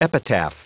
Prononciation du mot epitaph en anglais (fichier audio)
Prononciation du mot : epitaph